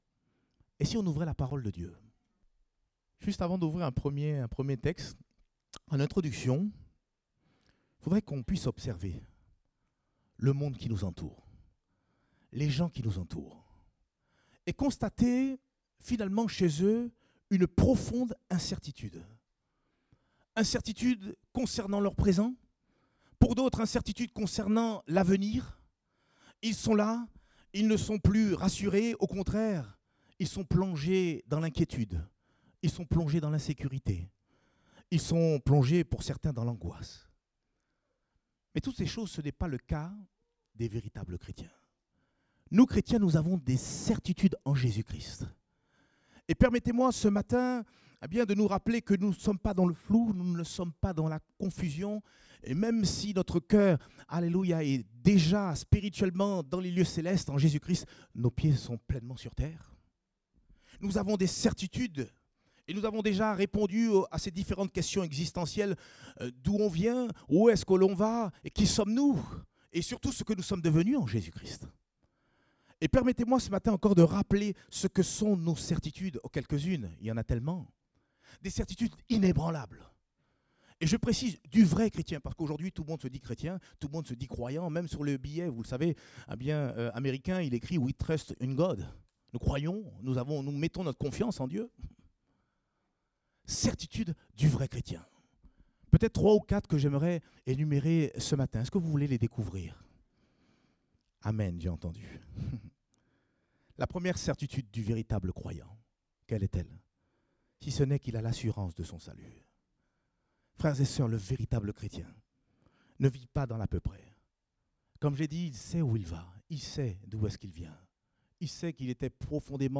Date : 10 juin 2018 (Culte Dominical)